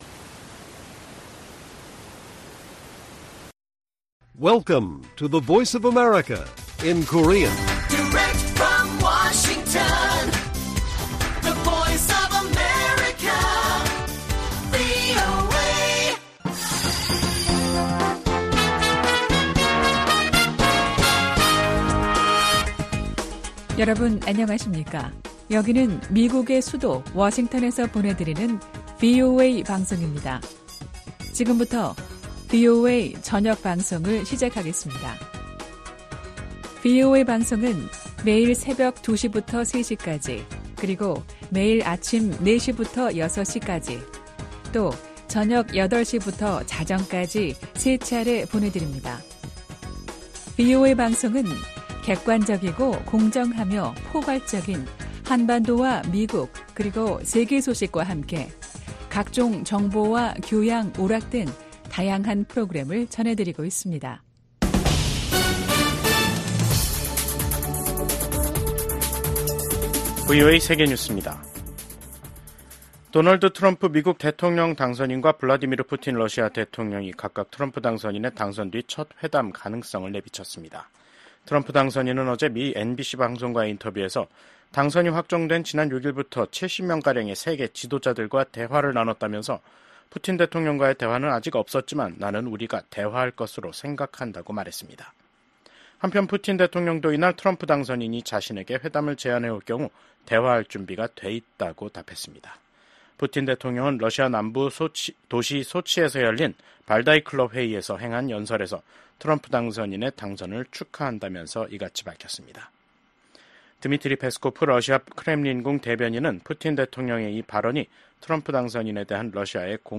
VOA 한국어 간판 뉴스 프로그램 '뉴스 투데이', 2024년 11월 8일 1부 방송입니다. 조 바이든 미국 대통령이 미국인들의 선택을 수용해야 한다며 도널드 트럼프 당선인의 대선 승리를 축하했습니다. 블라디미르 푸틴 러시아 대통령은 북한과의 합동 군사훈련이 가능하다고 밝혔습니다. 미국 국방부는 러시아에 파병된 북한군이 전장에 투입되면 합법적인 공격 대상이 된다고 경고했습니다.